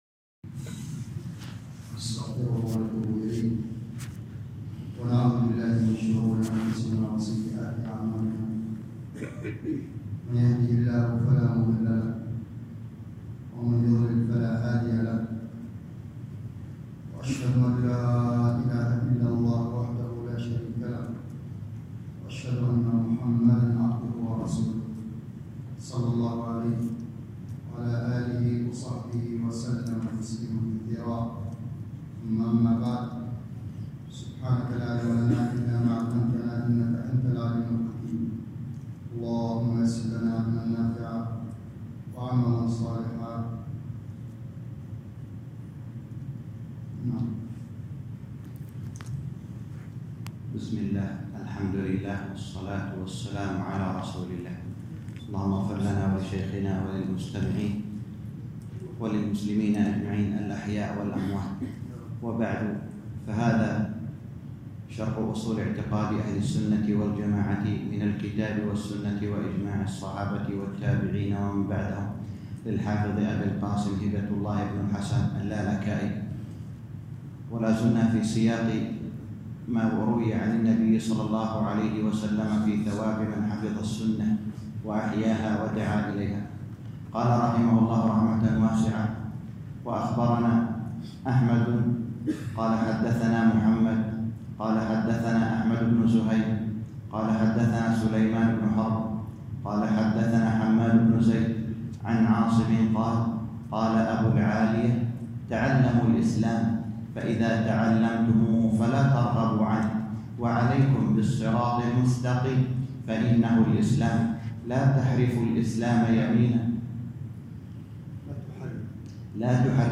الدرس العاشر - شرح أصول اعتقاد اهل السنة والجماعة الامام الحافظ اللالكائي _ 10